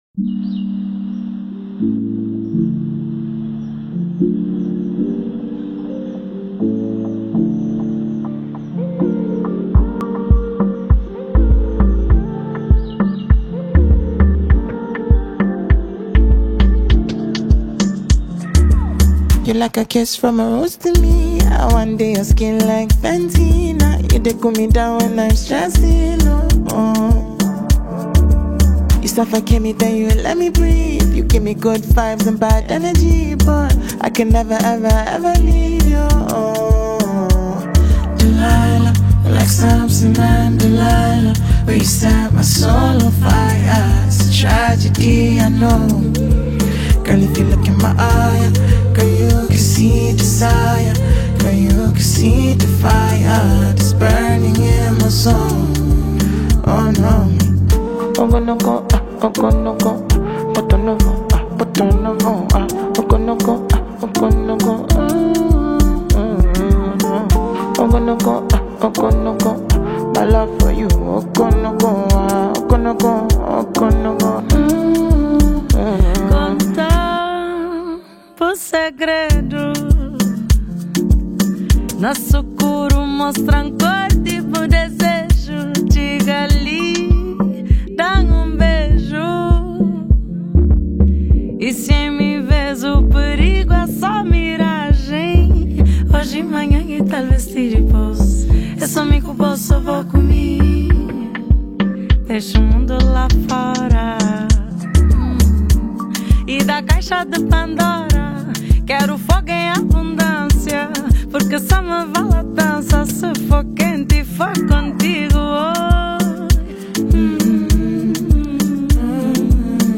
Nigerian melodious singer-producer
melodious vocals